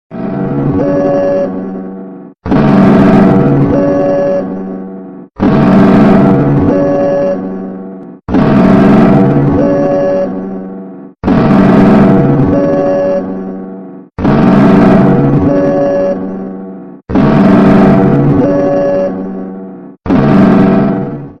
Greece-eas-alarm-but-even-more-ohio-made-with-voicemod Sound Button - Free Download & Play